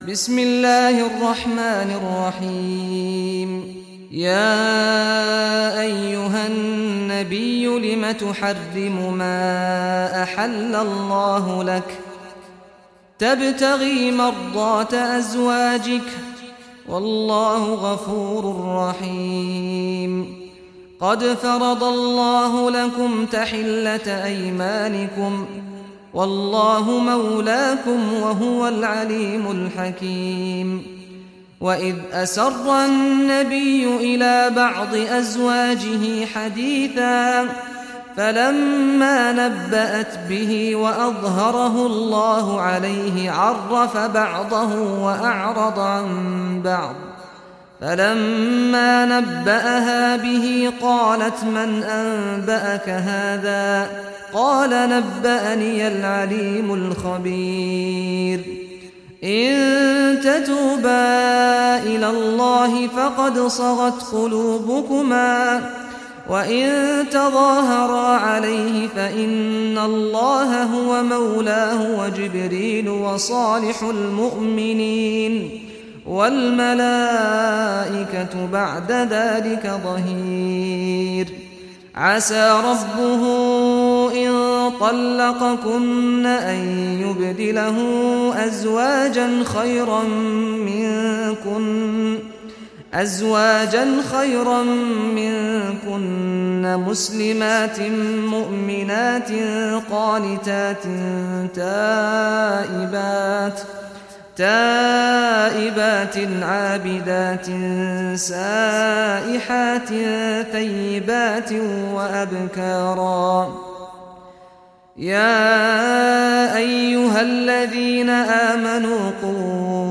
Surah At-Tahrim Recitation by Sheikh Saad Ghamdi
Surah At-Tahrim, listen or play online mp3 tilawat / recitation in Arabic in the beautiful voice of Sheikh Saad al Ghamdi.